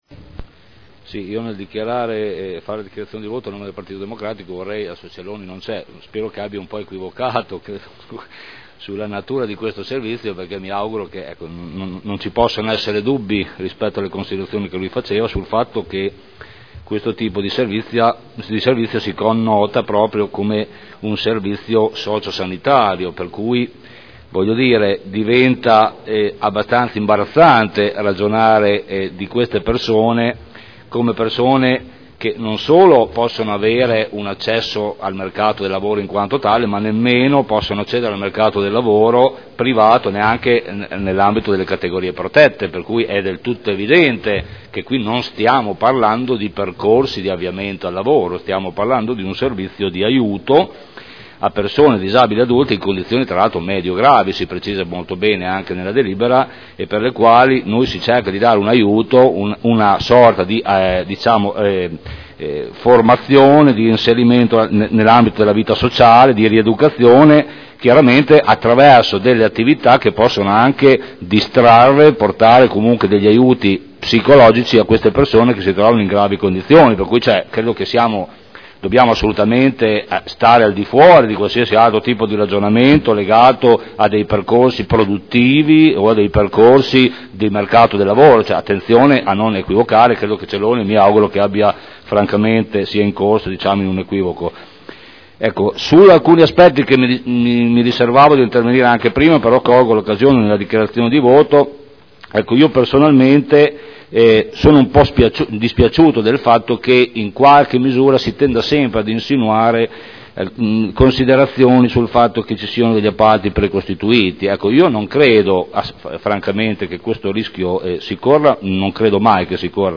Giancarlo Campioli — Sito Audio Consiglio Comunale